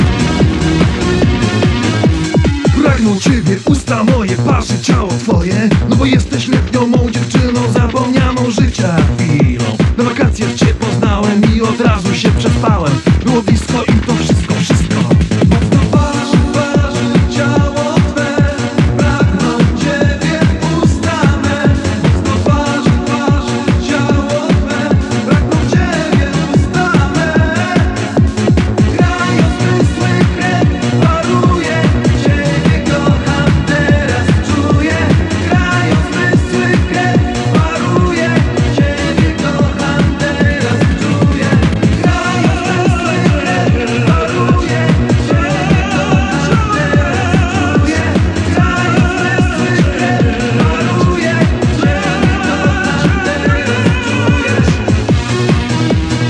Polska piosenka eurodance lata 90
kolejna piosenka z kasety, tym razem eurodance. jak ktoś zna wykonawcę i tytuł to niech napisze. z góry dzięki